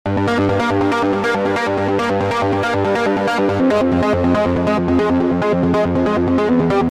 搏击合成器循环
描述：循环播放,舞蹈
Tag: 120 bpm Dance Loops Synth Loops 1.15 MB wav Key : Unknown